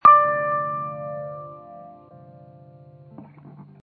Descarga de Sonidos mp3 Gratis: guitarra.